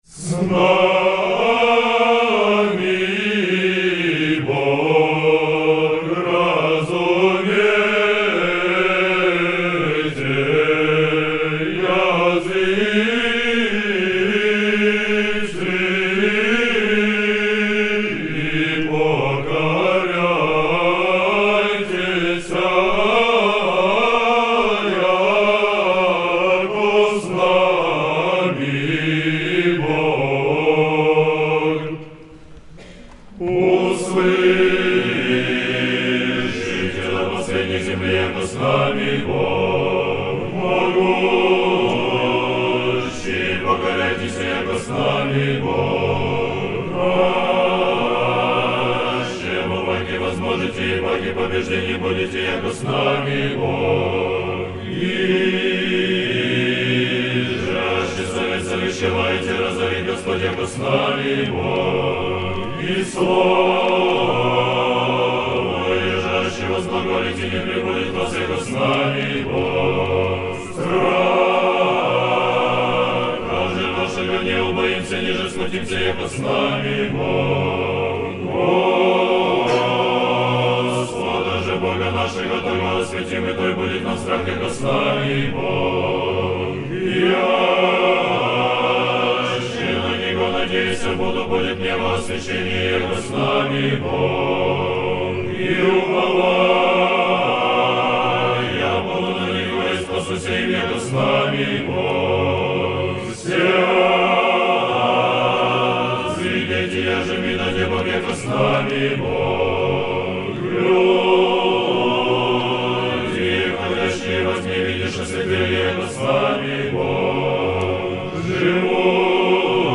Великое повечерие. Хор Сретенского монастыря и хор Сретенской духовной семинарии. Запись сделана в монастырском соборе Сретения Владимирской иконы Божией Матери.